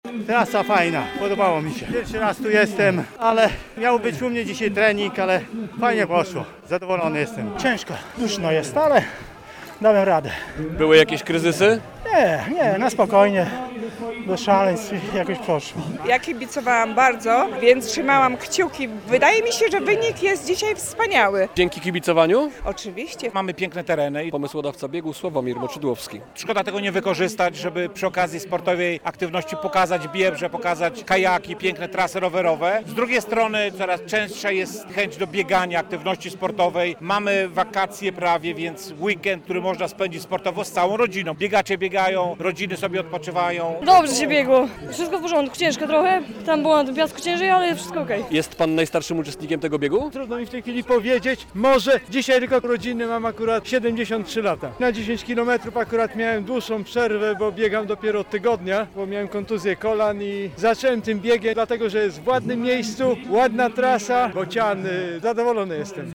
Trasa przebiega przez tereny Biebrzańskiego Parku Narodowego - relacja